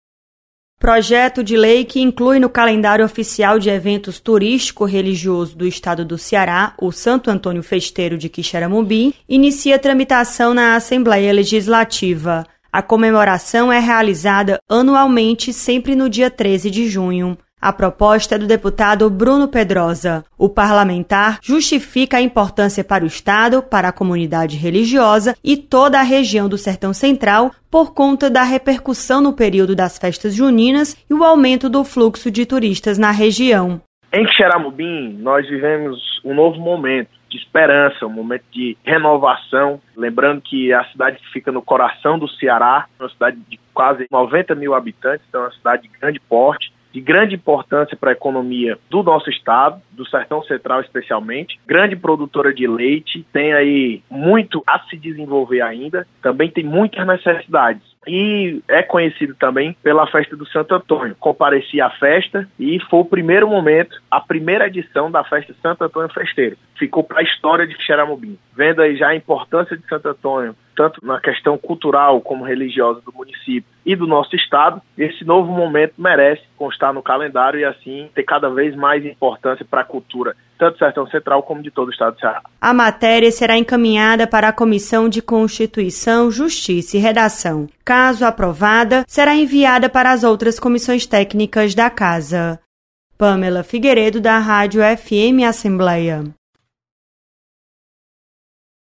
Deputado Bruno Pedrosa quer incluir Santo Antônio Festeiro de Quixeramobim no calendário oficial de eventos do Ceará. Repórter